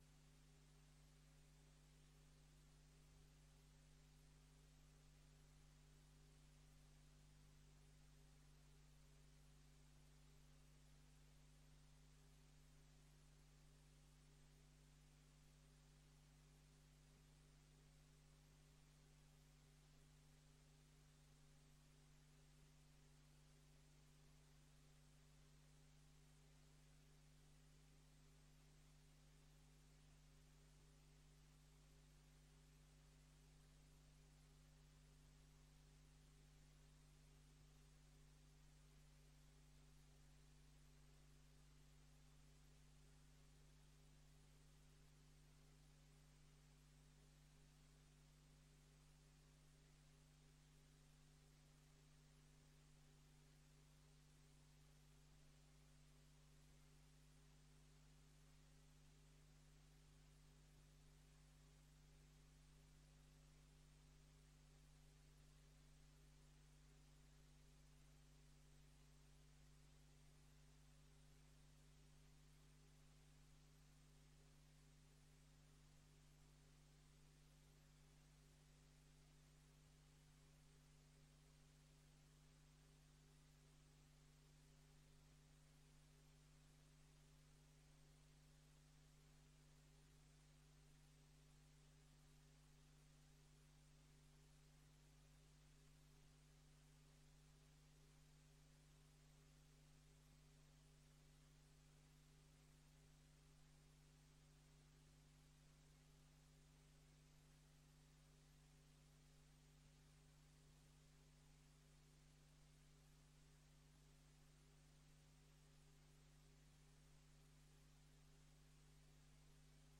Gemeenteraad 24 juni 2025 19:30:00, Gemeente Dinkelland
Download de volledige audio van deze vergadering
Locatie: Raadzaal